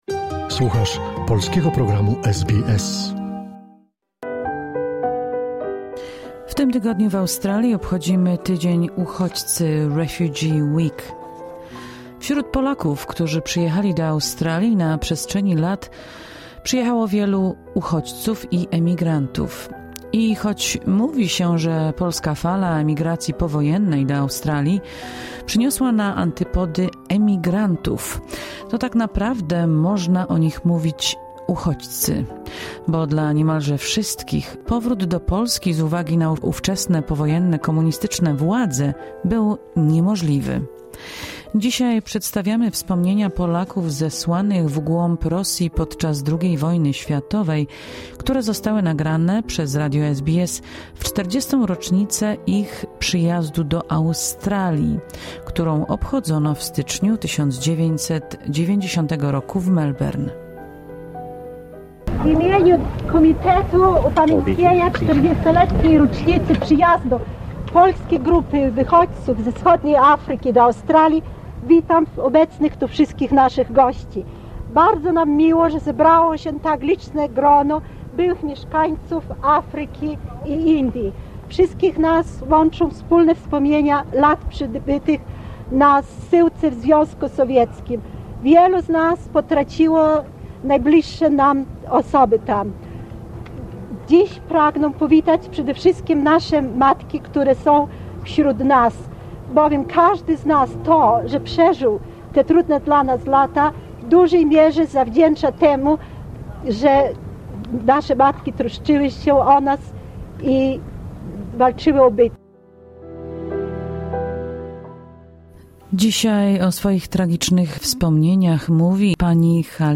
Z okazji Refugee Week czyli Tygodnia Uchodźcy przedstawiamy wspomnienia Polaków zesłanych w głąb ZSSR podczas II wojny światowej, którzy później trafili do Australii. Wspomnienia zostały nagrane przez radio SBS w 40 rocznice ich przyjazdu do Australii, którą obchodzono w styczniu 1990 roku w Melbourne.